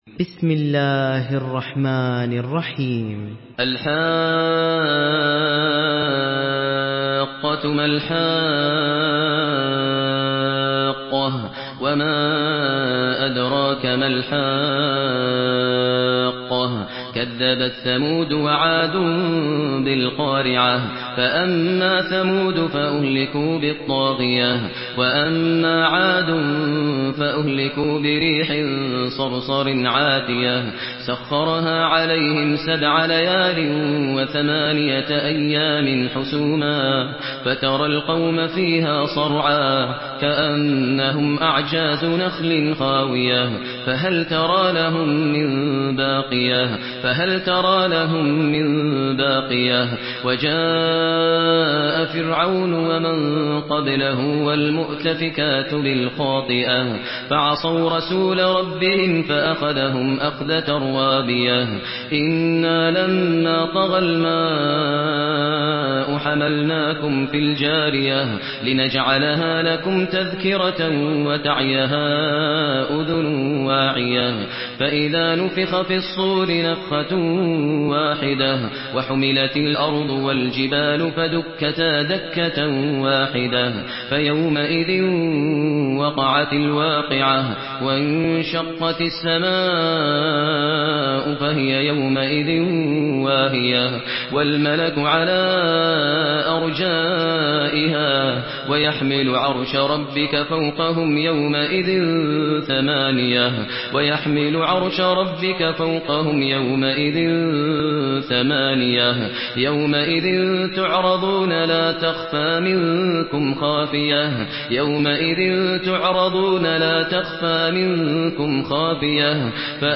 Surah Hakka MP3 by Maher Al Muaiqly in Hafs An Asim narration.
Murattal Hafs An Asim